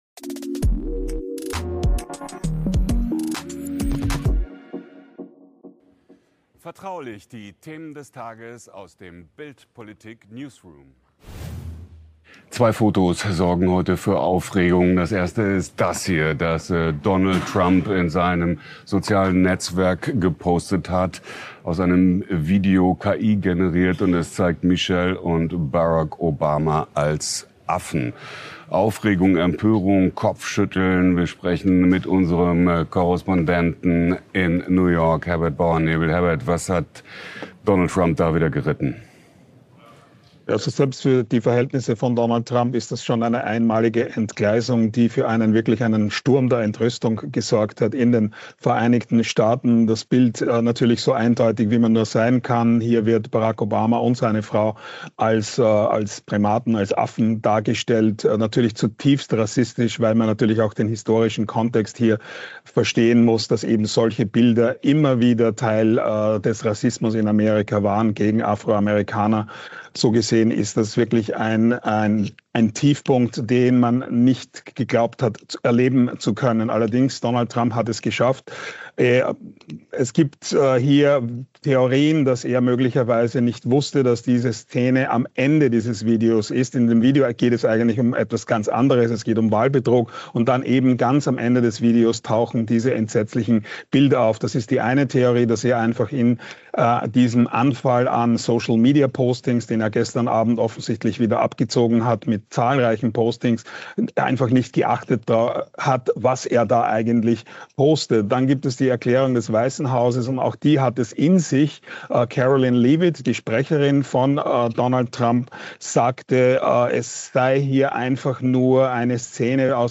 Der Politik-Vodcast: Vertraulich! Darum hasst Trump die Obamas so sehr ~ Vertraulich - der tägliche Politik-Talk Podcast